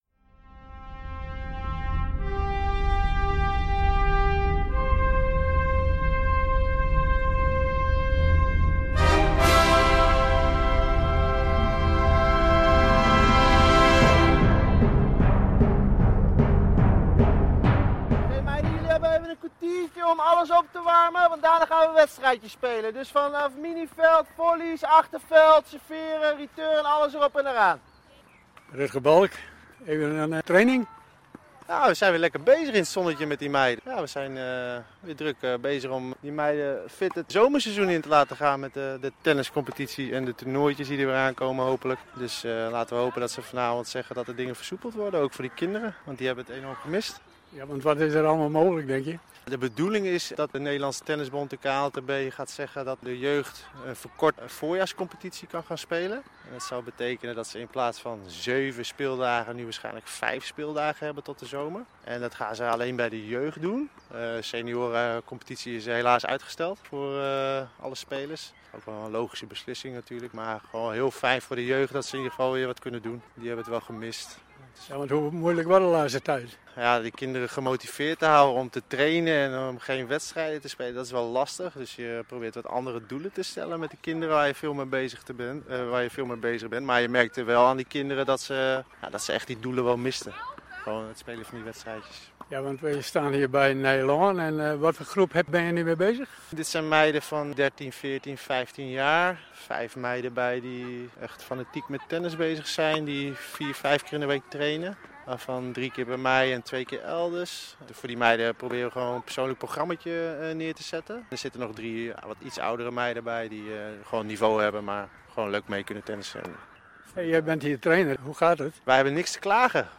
Underweis - Interviews - Sport - Onderweg